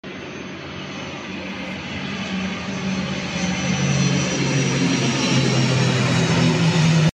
Airbus C-295, Serbian Air-Force.